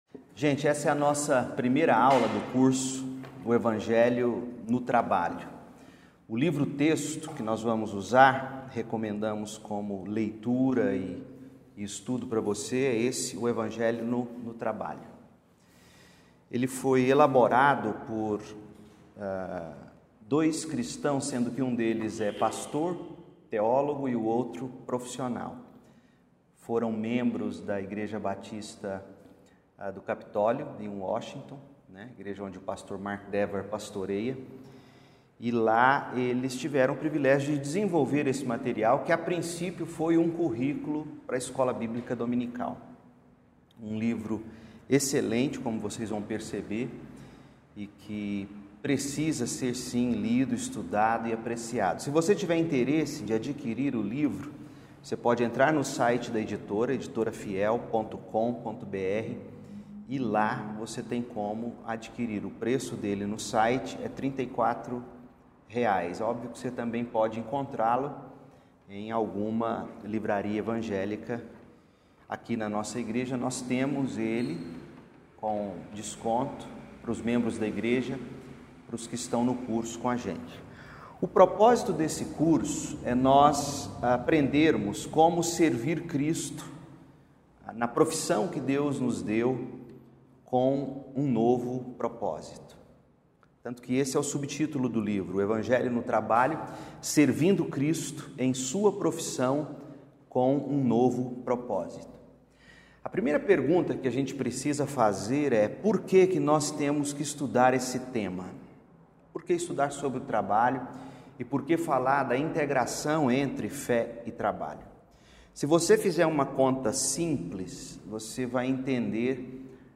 Aula 1 | Introdução - o desafio